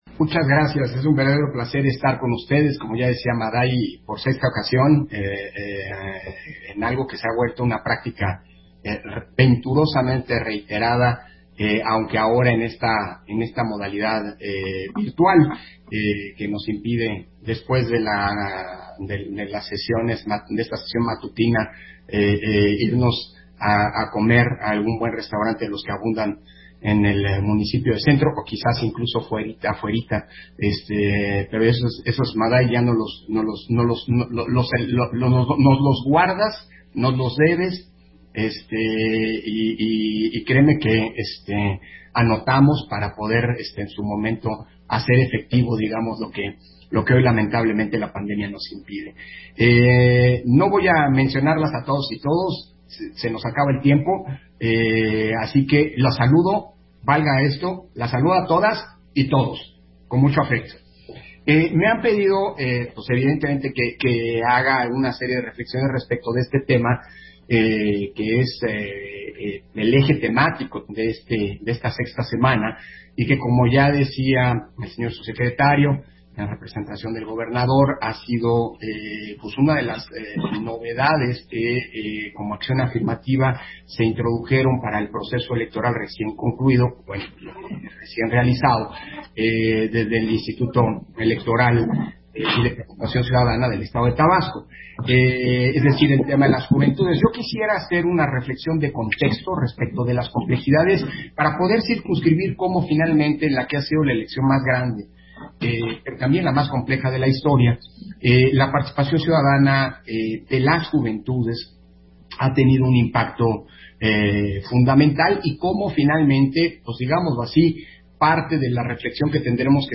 Intervención de Lorenzo Córdova, en la conferencia: La participación de la juventudes en la democracia, su importancia, trascendencia y retos